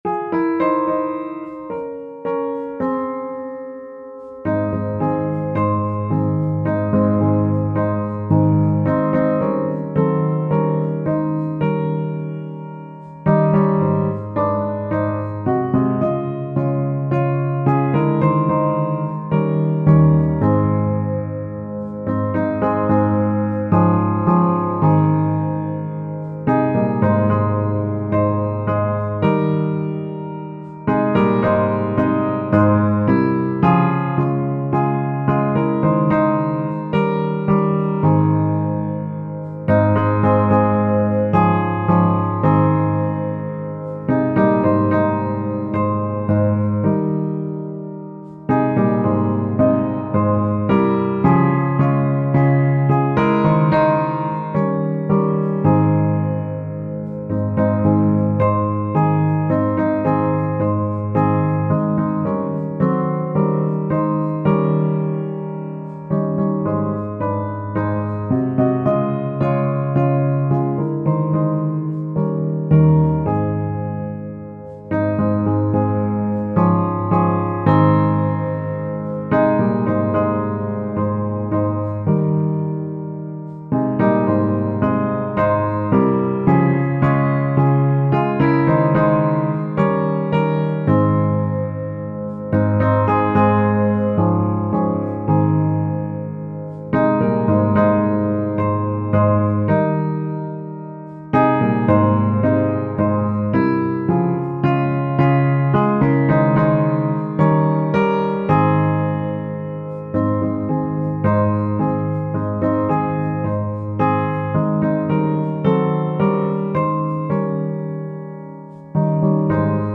Tempo: Langzaam